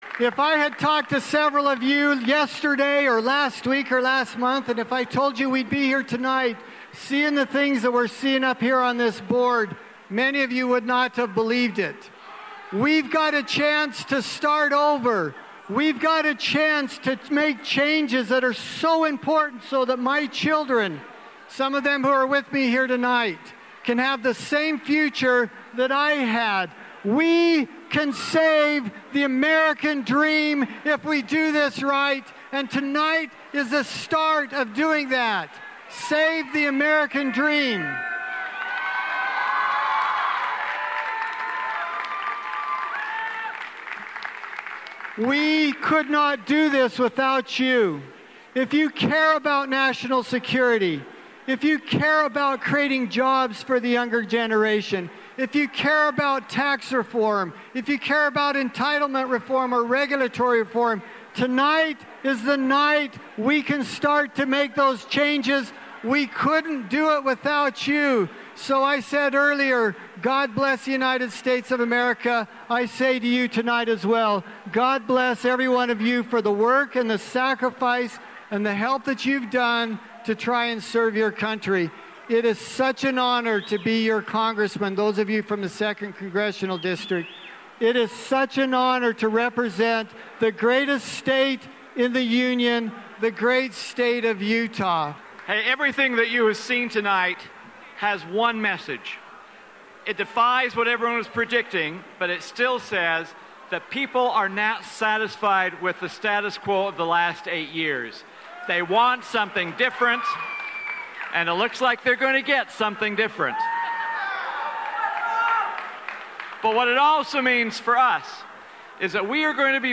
Members of Utah's congressional delegation thank the crowd after posting solid victories in their races. Here are portions of the victory speeches from Chris Stewart and Rob Bishop.